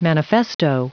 Prononciation du mot manifesto en anglais (fichier audio)
Prononciation du mot : manifesto
manifesto.wav